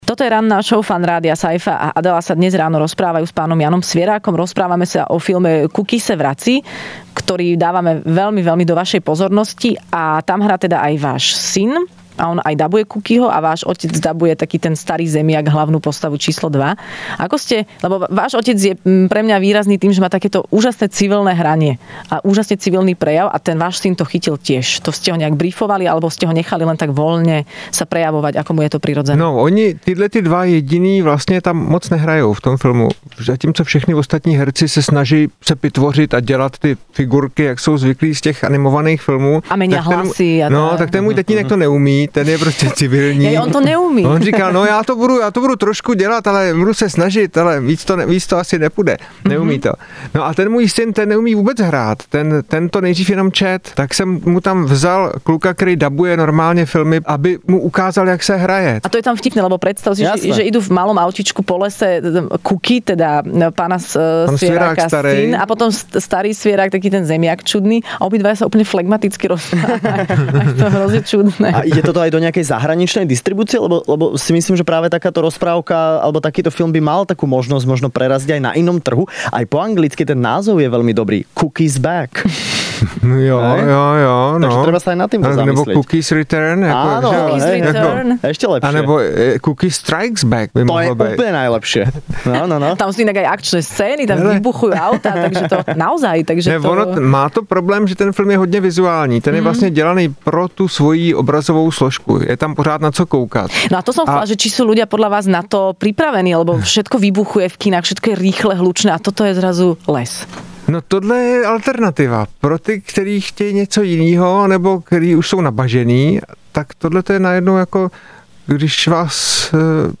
Hosťom v rannej šou Fun rádia bol režisér Jan Svěrák, ktorý porozprával o svojom novom filme Kuky se vrací...